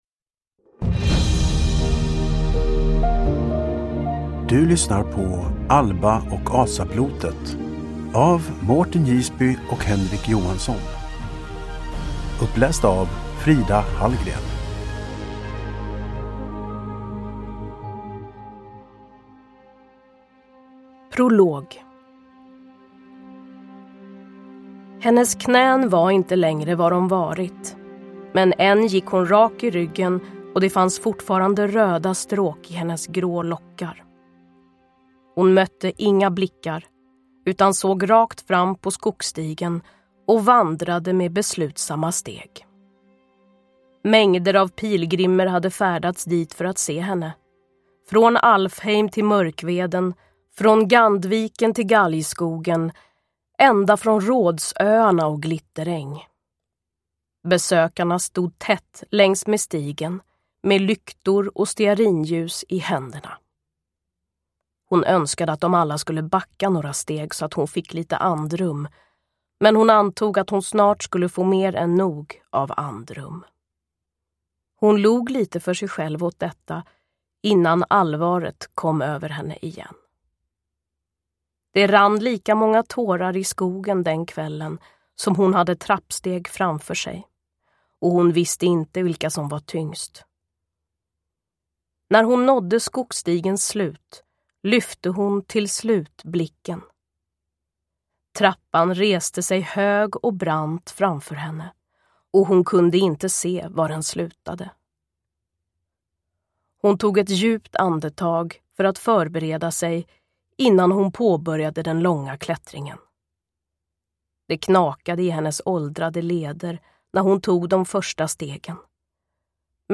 Alba och asablotet – Ljudbok – Laddas ner